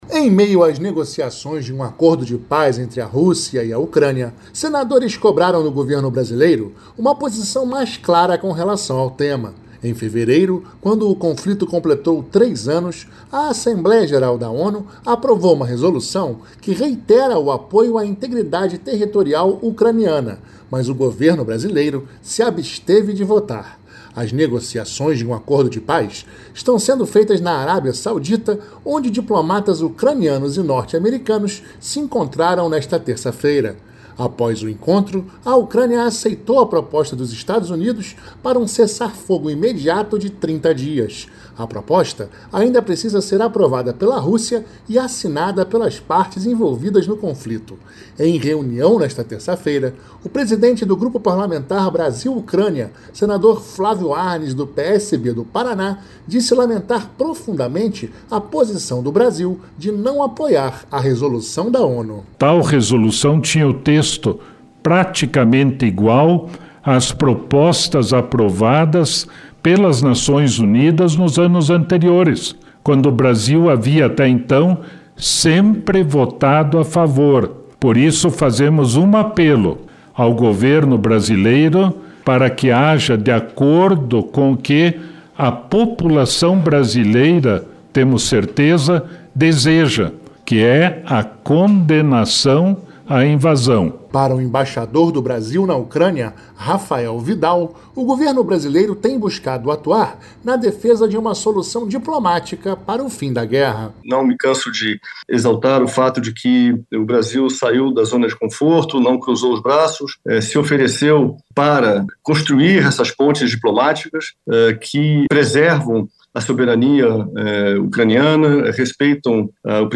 Em reunião nesta terça-feira (11), o presidente do Grupo Parlamentar Brasil Ucrânia, senador Flávio Arns (PSB-PR), disse lamentar profundamente a posição do Brasil.